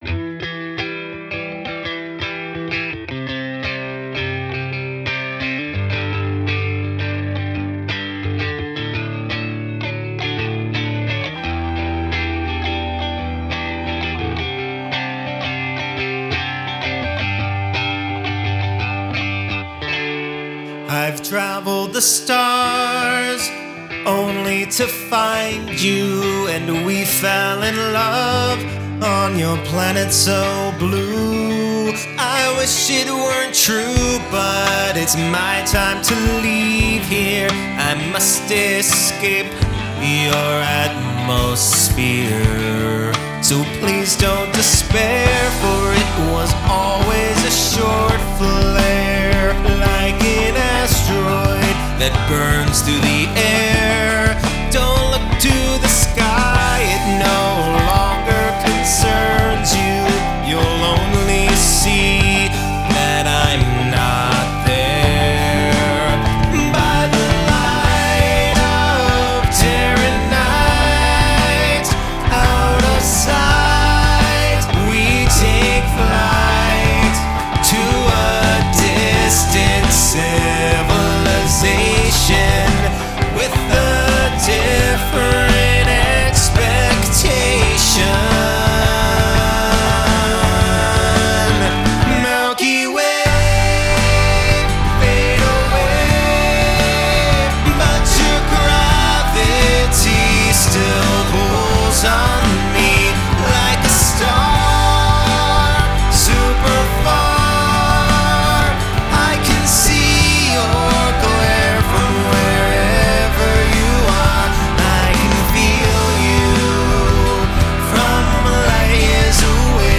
Soft Rock
185 BPM
E minor
Lead Vocals, Guitar, Piano, Synths
Background Vocals
Bass Guitar
Master Audio